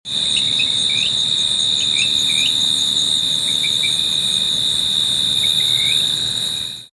دانلود صدای قورباغه در شب از ساعد نیوز با لینک مستقیم و کیفیت بالا
جلوه های صوتی
برچسب: دانلود آهنگ های افکت صوتی انسان و موجودات زنده دانلود آلبوم صدای قورباغه (قور قور) از افکت صوتی انسان و موجودات زنده